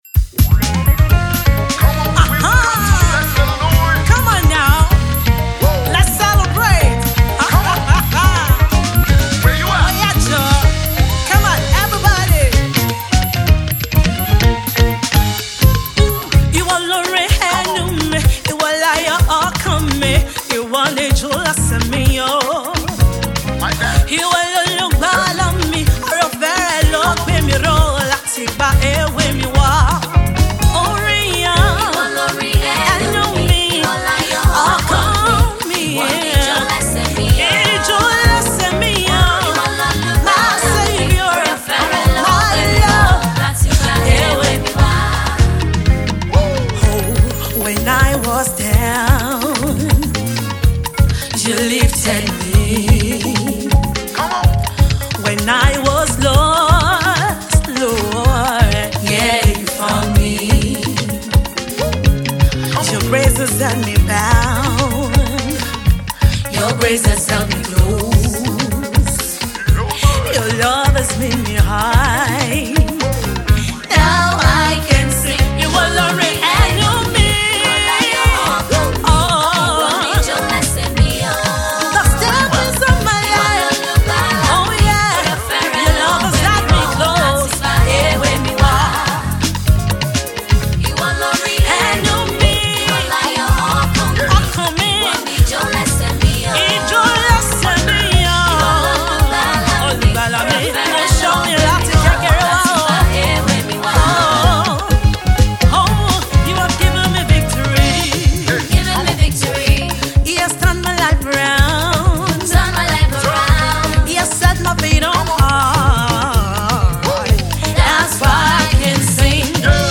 gospel singer